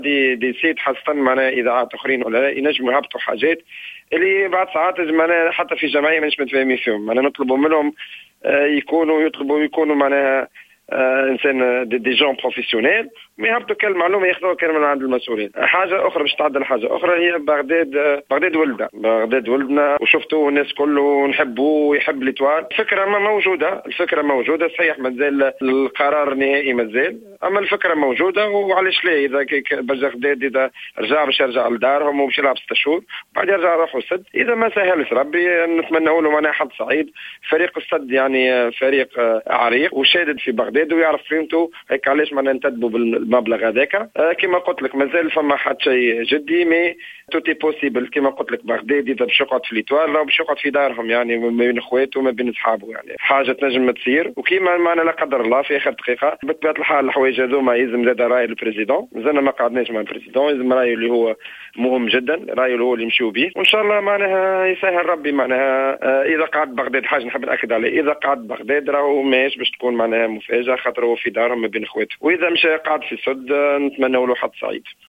و بإتصالنا بالمدير الرياضي للنجم زياد الجزيري لم يؤكد الخبر حيث أفادنا بالتصريح التالي حول الموضوع: